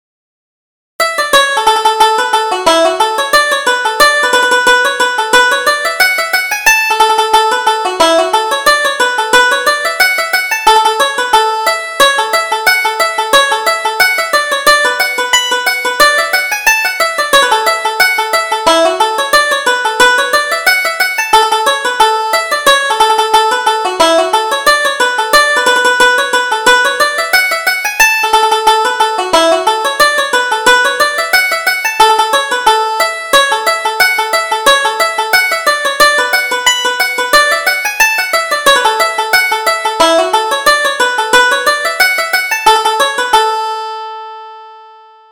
Reel: The Mason's Apron